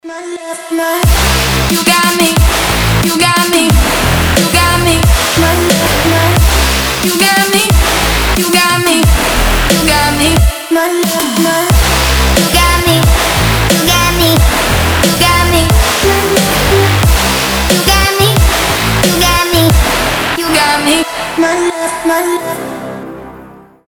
• Качество: 320, Stereo
громкие
мощные
Electronic
future bass
Классный взрывной трэп